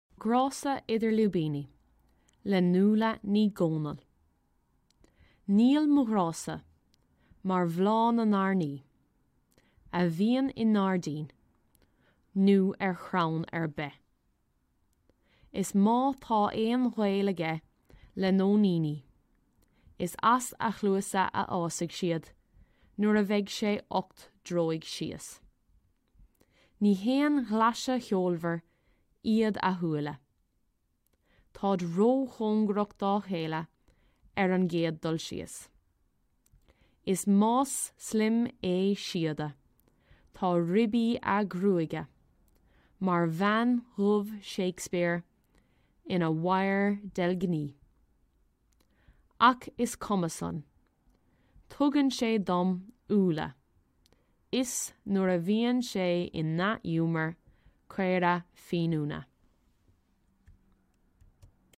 Reading of the poem - Mo Ghrása (Idir Lúibíní)
This is a recording of an Irish teacher and State Examiner reading Mo Ghrása (Idir Lúibíní) to help you with the Poetry Reading section of the Irish oral exam. Listening to this recording will help you learn the correct pronunciation and rhythm of speech.